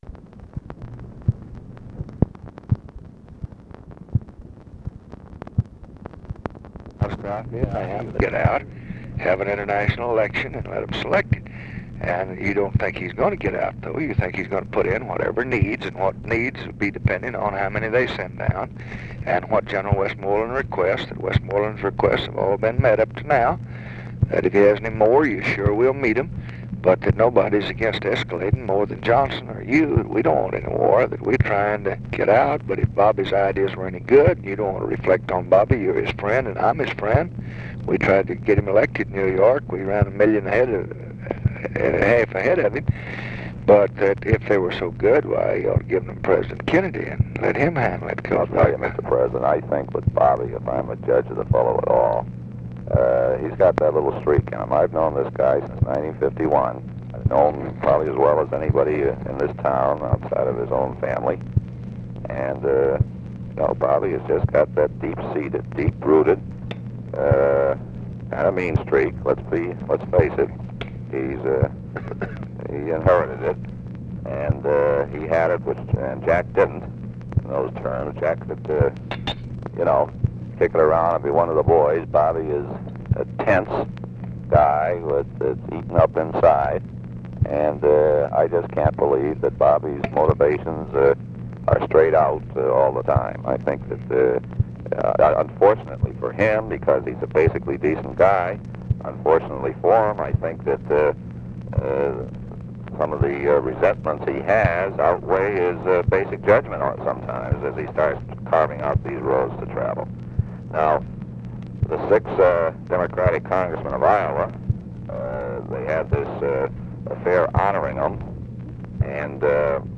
Conversation with LARRY O'BRIEN, February 28, 1966
Secret White House Tapes